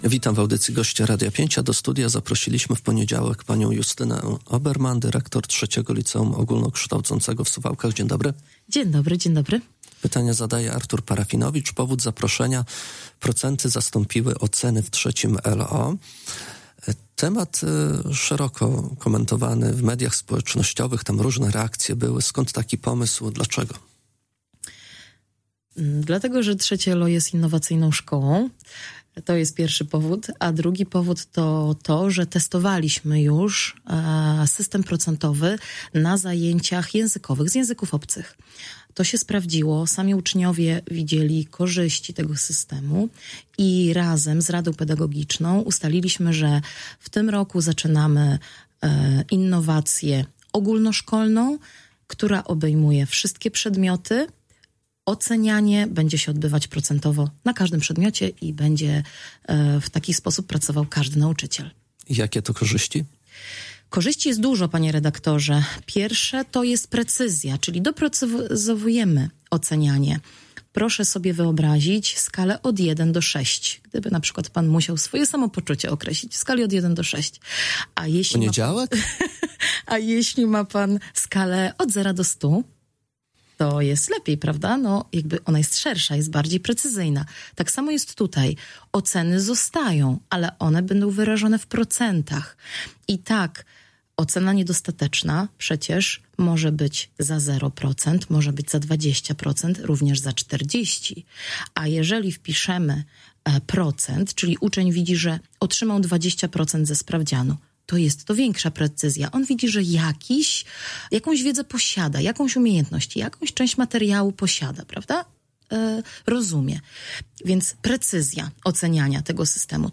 O szczegółach zmiany dyrektor opowiadała w audycji Gość Radia 5: https